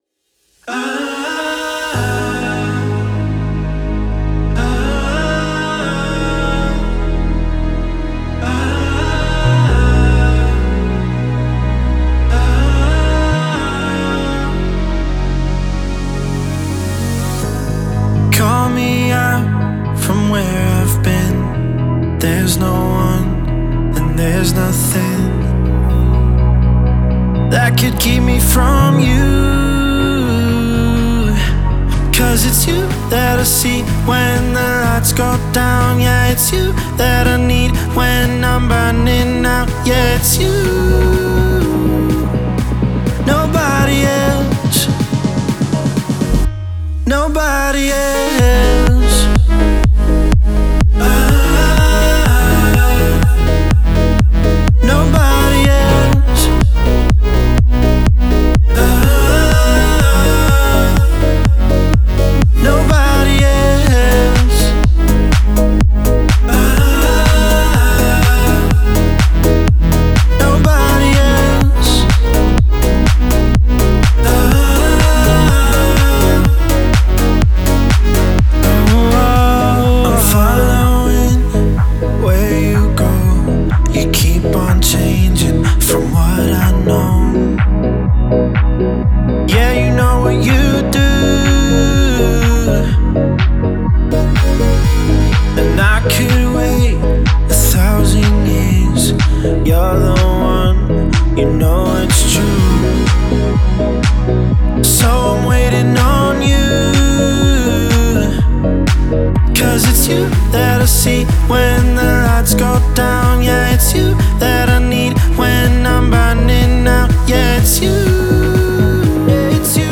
проникновенная поп-баллада
наполненная искренними эмоциями и мелодичным звучанием.